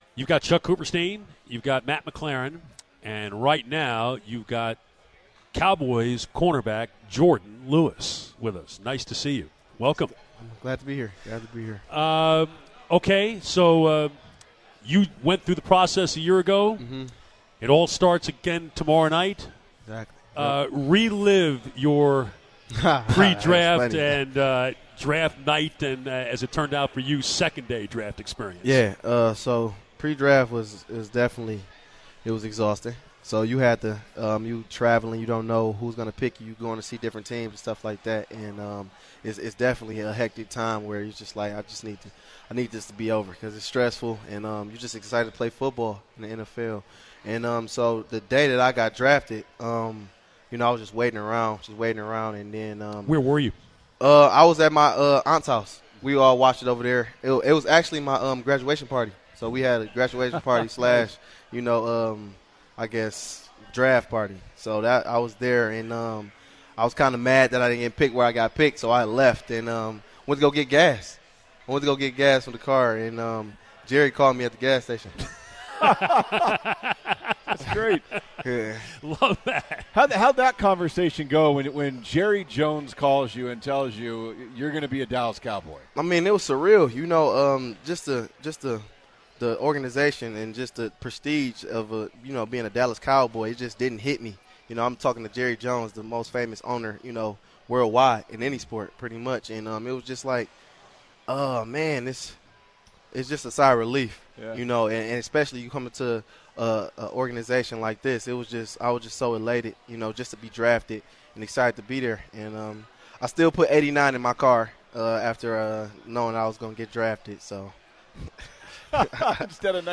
at Pin Stack in Plano at the ESPN Experience to talk all things Cowboys including Dez Bryant and the NFL Draft.